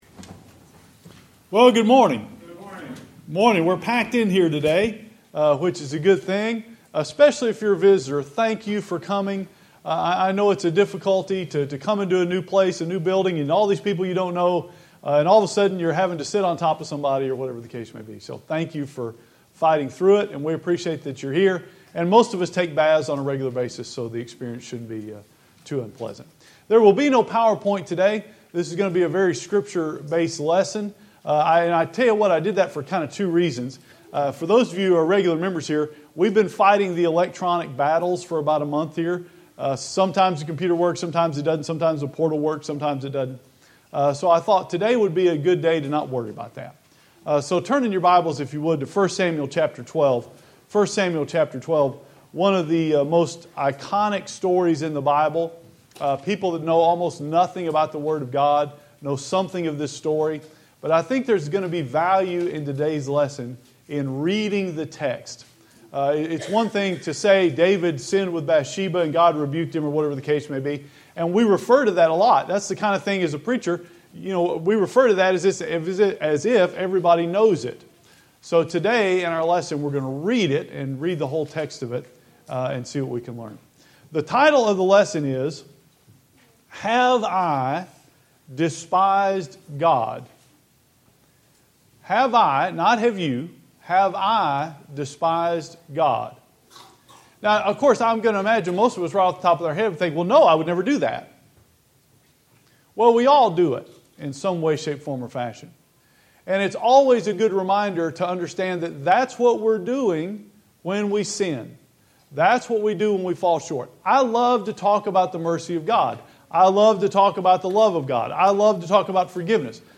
Our recording process missed our evening service and half of this sermon.